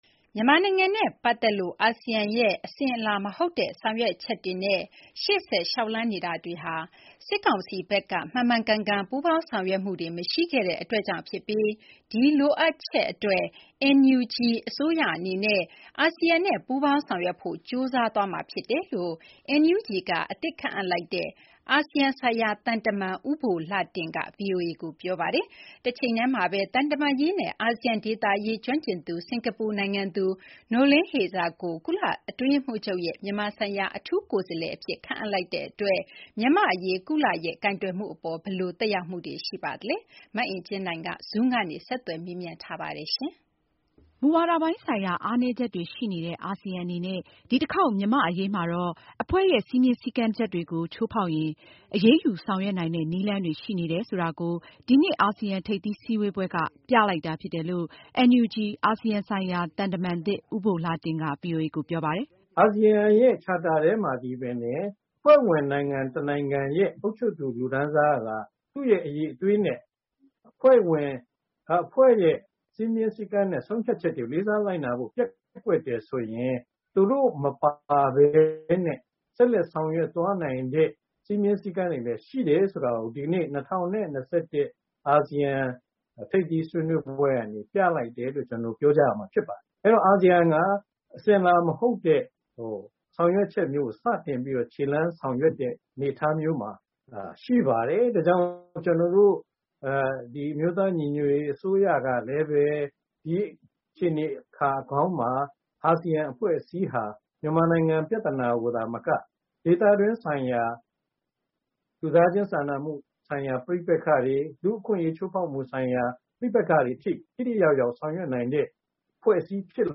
Zoom ကနေ ဆက်သွယ်မေးမြန်းထားပါတယ်။